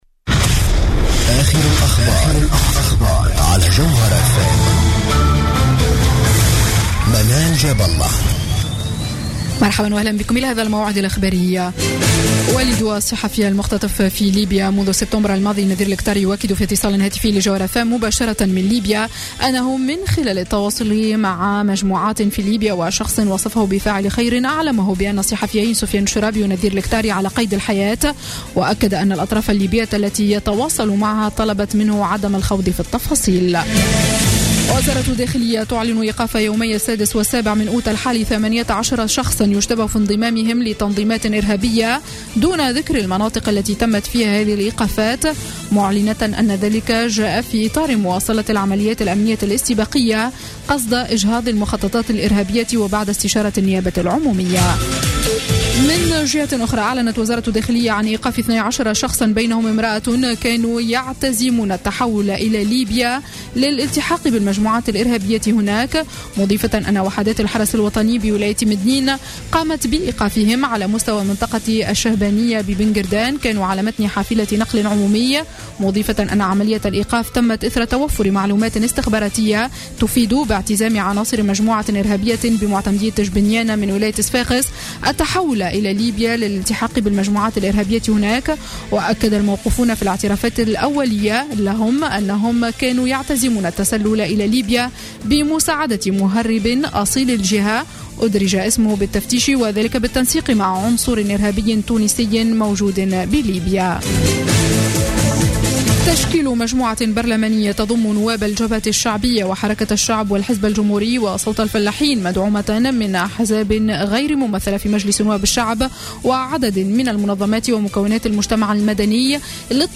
نشرة أخبار السابعة مساء ليوم الجمعة 7 أوت 2015